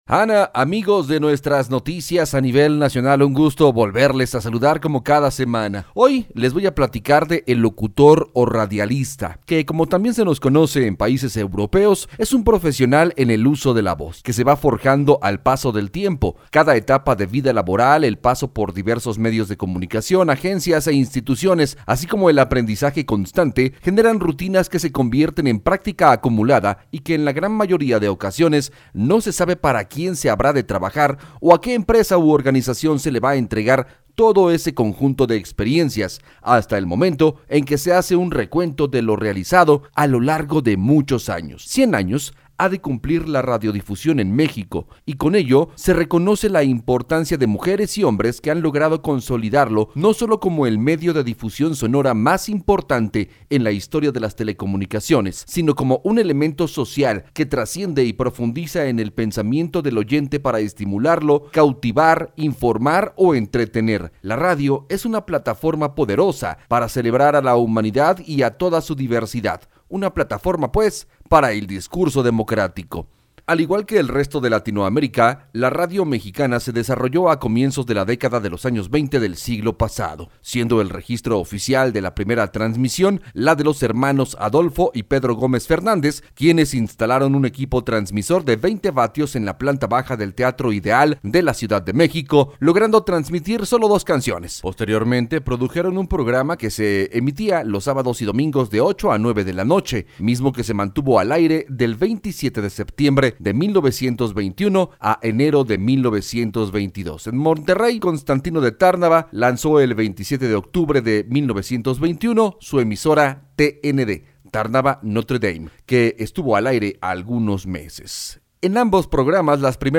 La única columna radiofónica que habla de la radio en México y nos la presenta